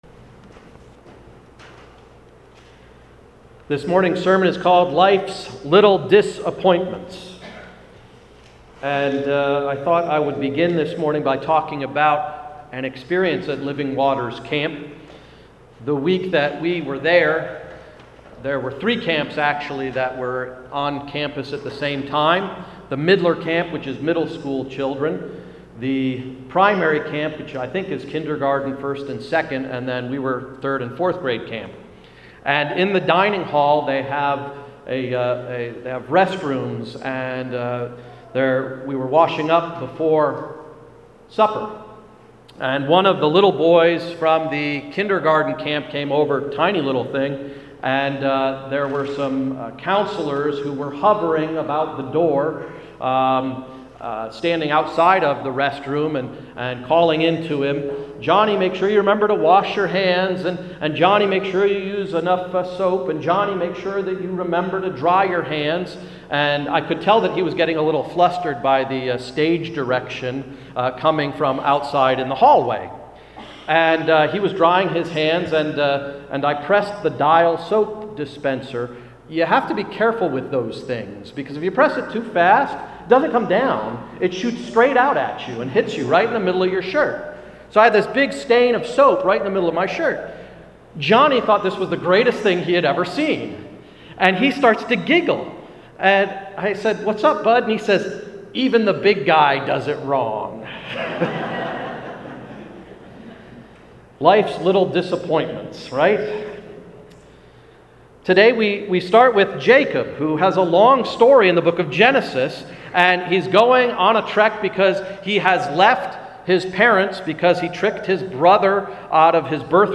I’m not sure if this story is meant to be funny or depressingly sad. I took the latter tack in this sermon.